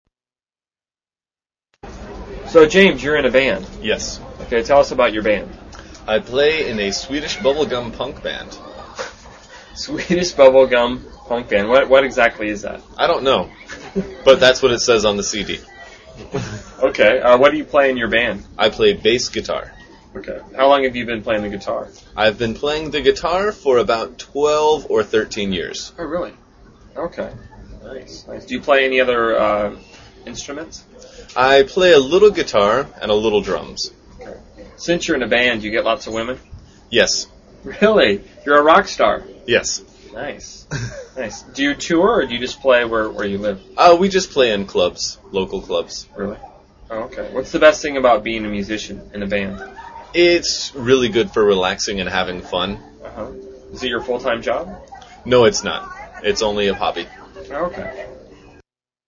英语高级口语对话正常语速11:乐队（MP3）